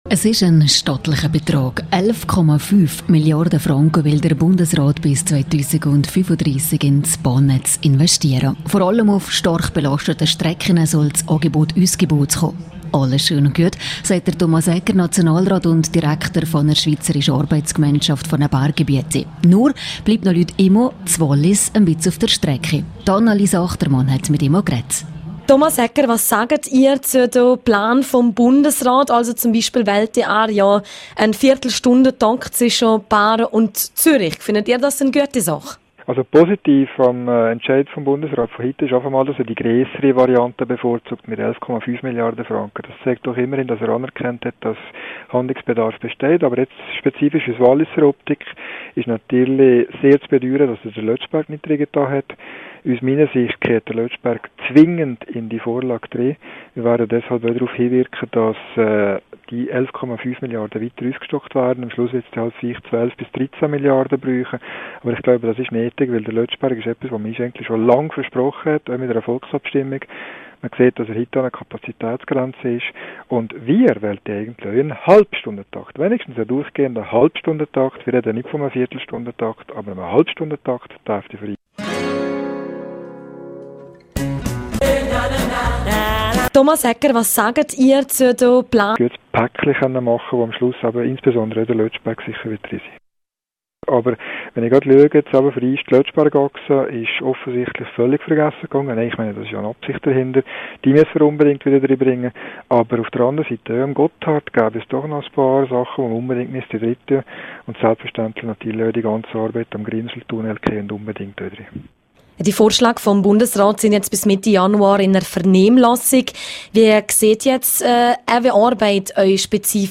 Viola Amherd zur Fertigstellung des Lötschberg-Basistunnels (Quelle: rro) Interview mit Thomas Egger, Nationalrat und Direktor der Schweizerischen Arbeitsgemeinschaft für Ber (Quelle: rro)